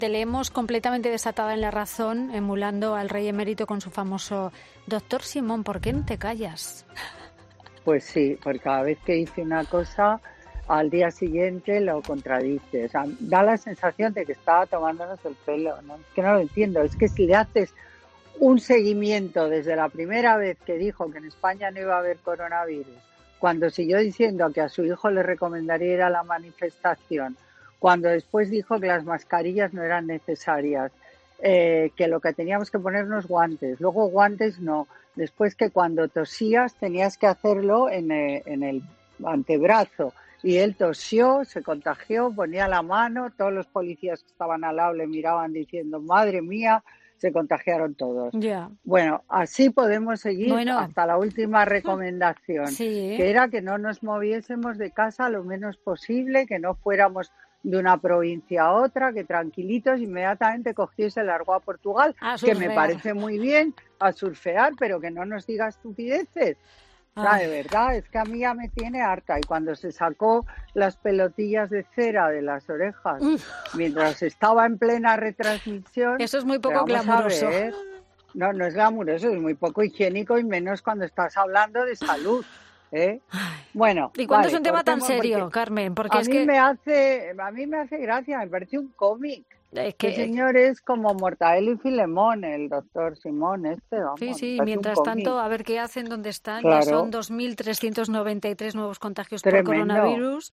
Un momento de tensión en el que la colaboradora de Fin de Semana cada vez se iba calentando más: “De verdad, es que me tiene harta”.
“A mí me hace gracia, me parece un cómic, es como Mortadelo y Filemón”, concluía entre las risas del equipo.